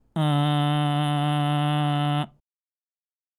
最後に、喉頭は小人の状態でグーの声を使いながら鼻、鼻口、口の順に出していく。
※喉頭は小人の状態でグーで鼻